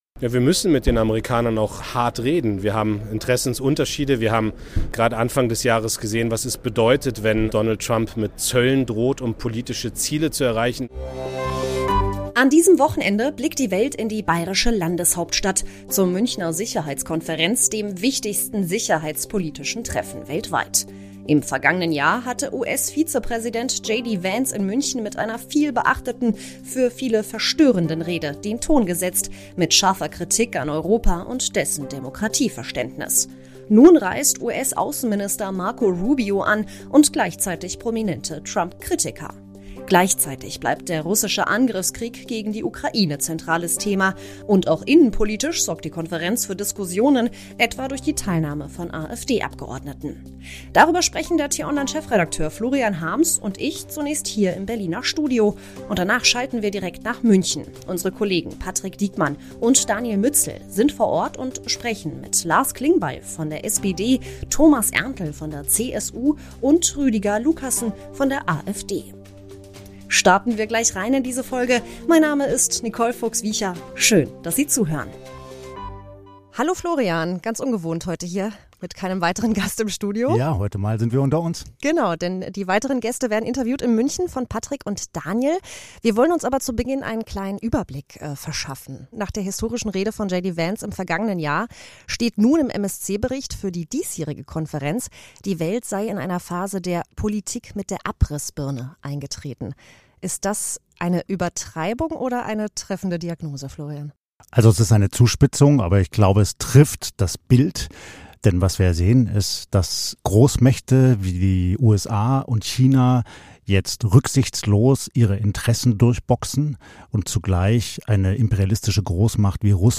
Unsere Reporter sprechen vor Ort in München mit: Lars Klingbeil (SPD) über europäische Stärke und den Umgang mit Trump.